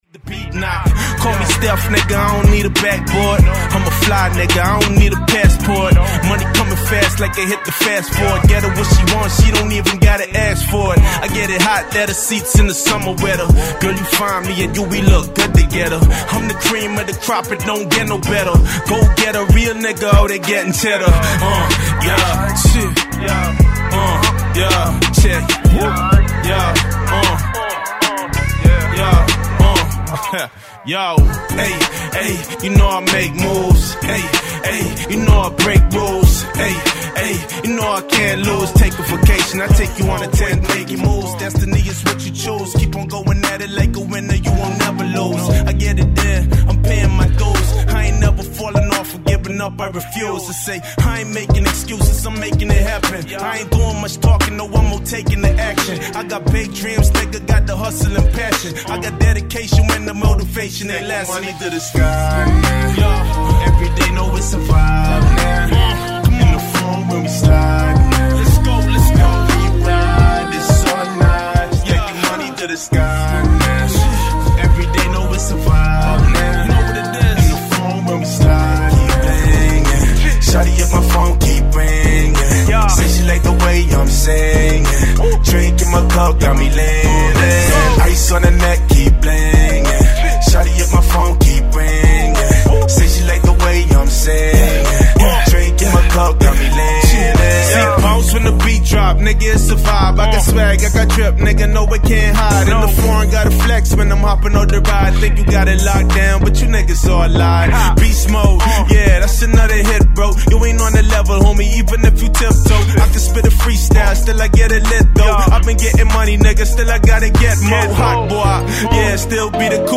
All acapellas bpm labeled and professionally recorded